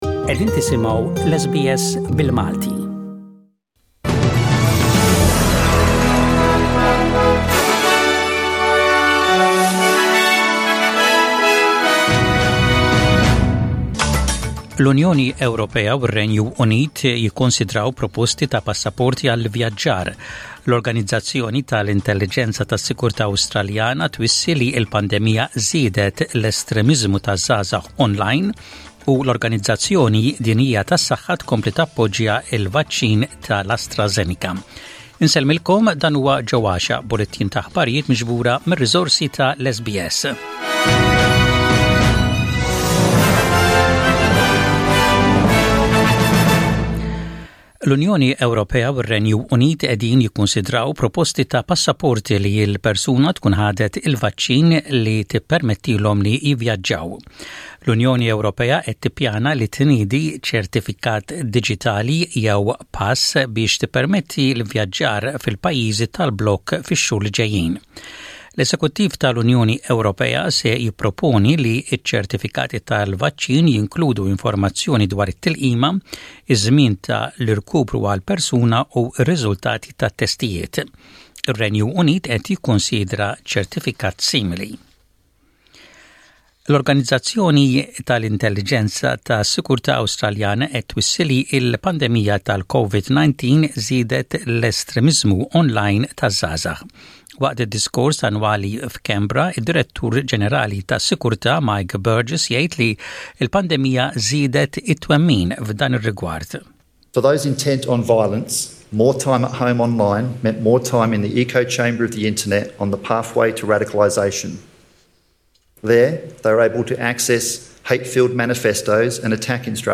SBS Radio | Maltese News: 19/03/21